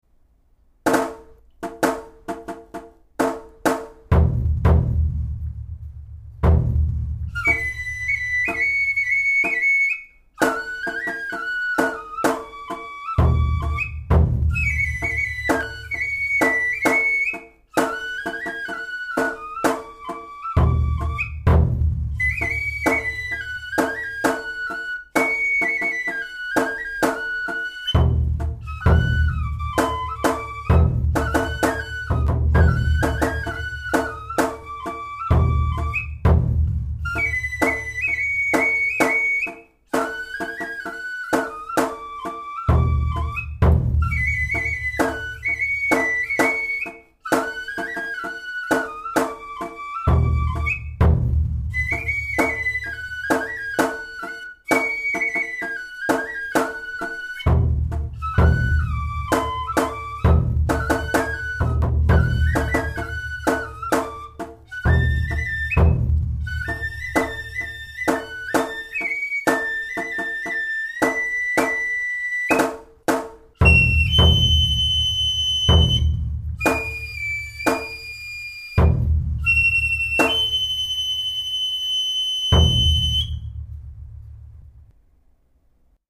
神楽・お囃子の太鼓伴奏を、シーケンサーとソフトサンプラーで作ってみました。
またDTMソフト（Audacityでもできます）などのソフトを使えば、自分が吹いた笛の音とこの太鼓伴奏音源をミックスさせることもできます。
太鼓伴奏音源　＋　笛　　−＞